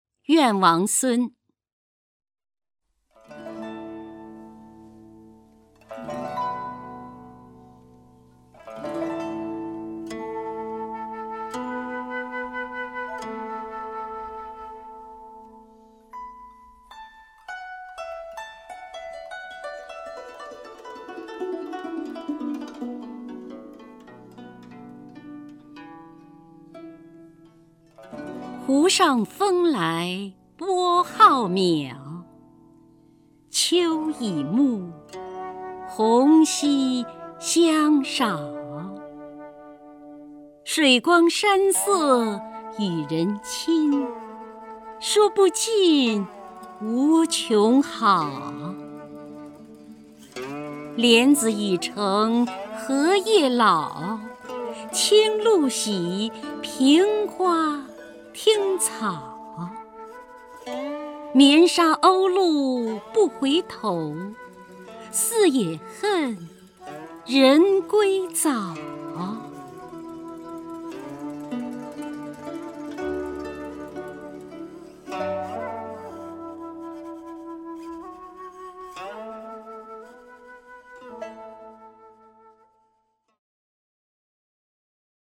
首页 视听 名家朗诵欣赏 姚锡娟
姚锡娟朗诵：《怨王孙·湖上风来波浩渺》(（南宋）李清照)　/ （南宋）李清照